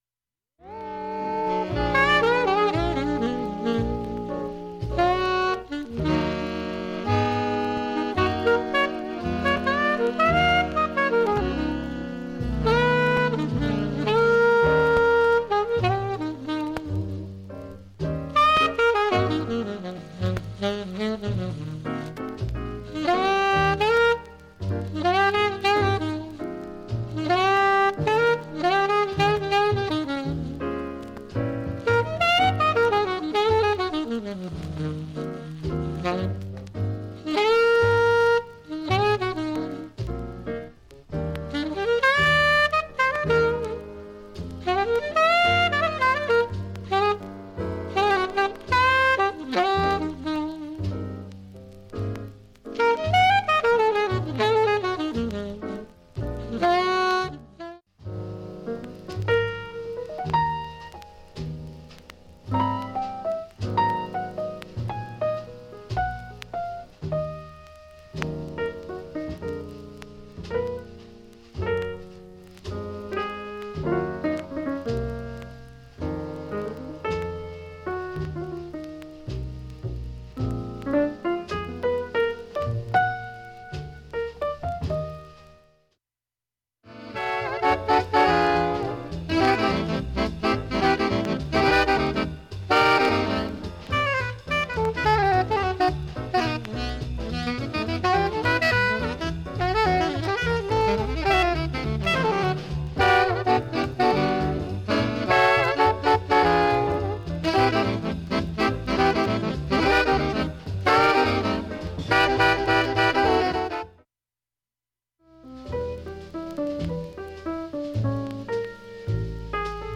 盤面きれいです音質良好全曲試聴済み。
ほか５回までのかすかなプツが２箇所
単発のかすかなプツが５箇所